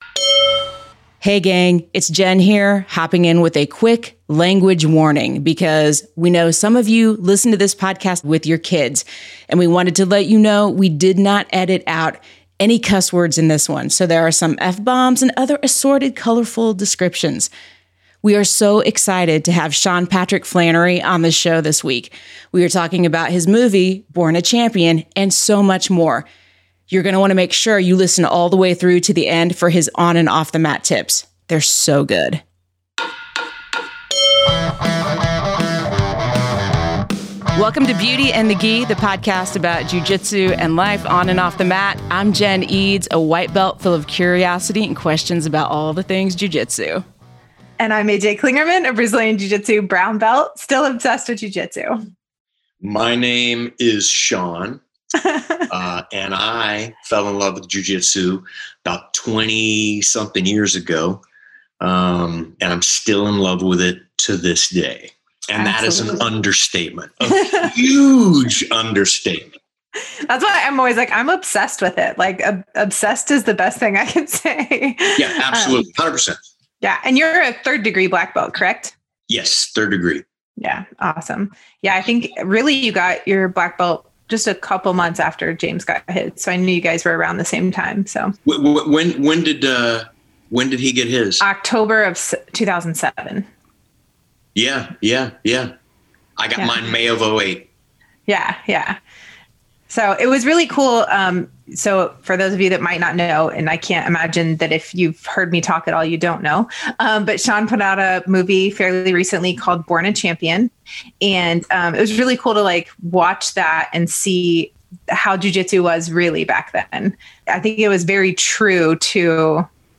Sean Patrick Flanery is talking to us about making Born a Champion, his movie about Brazilian Jiu-Jitsu. He shares some great on and off the mat wisdom as we wrap up the episode.